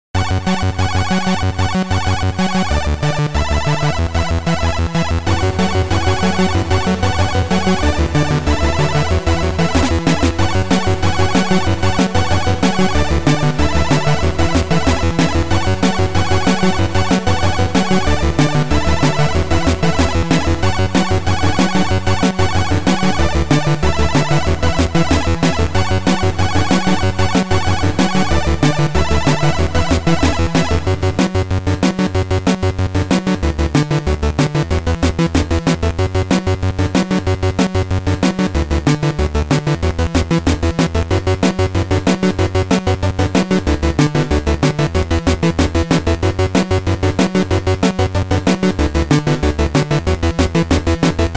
Sound Format: Noisetracker/Protracker
Sound Style: Chip